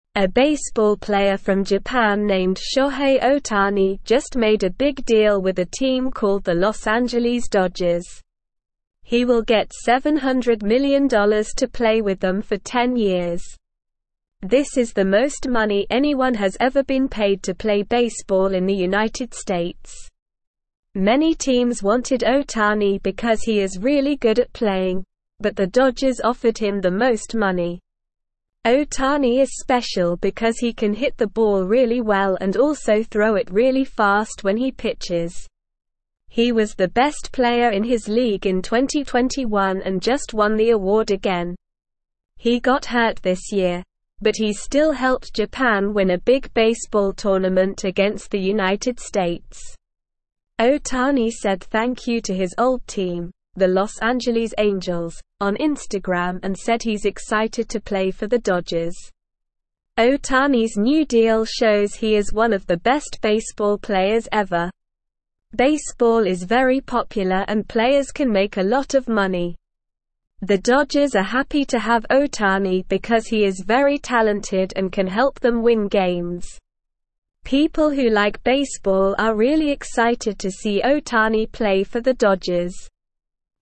Slow
English-Newsroom-Lower-Intermediate-SLOW-Reading-Japanese-Baseball-Star-Signs-Big-Deal-with-Los-Angeles.mp3